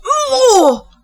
SFX惊讶哦(surprised oh)音效下载